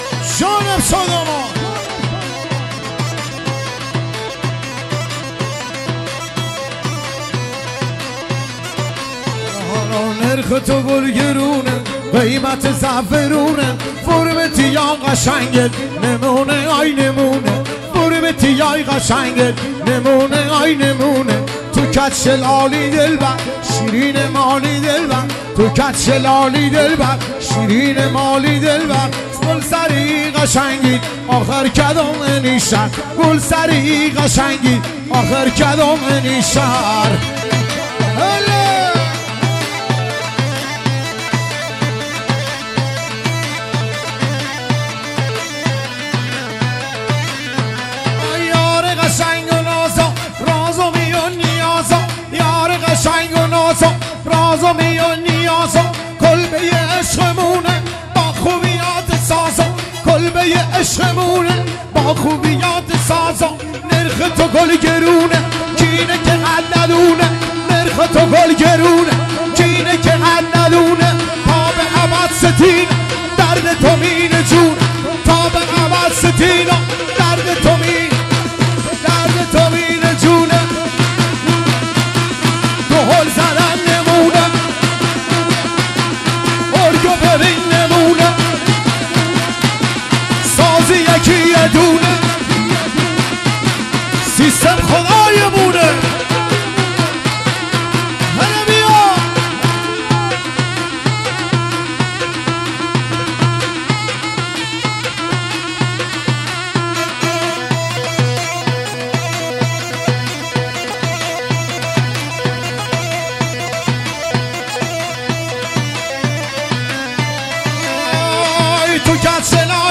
لری عروسی